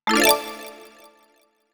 TextMessage.wav